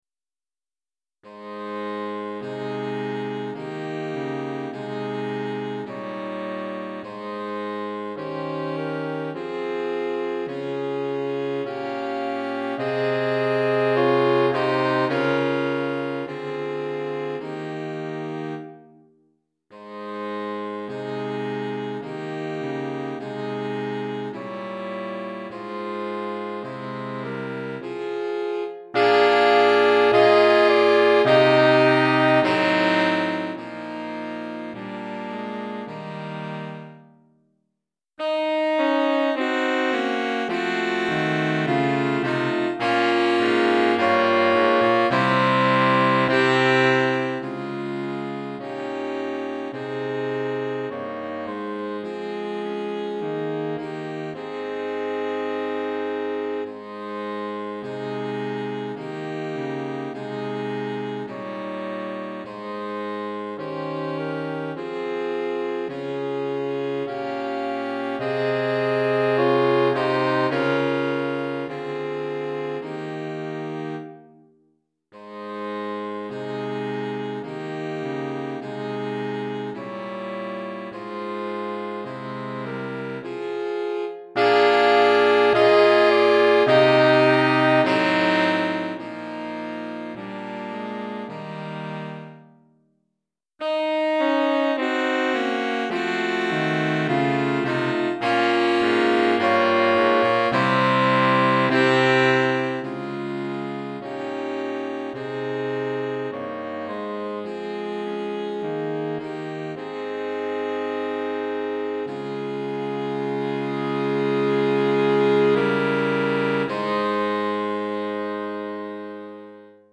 Saxophone Soprano, Saxophone Alto, Saxophone Ténor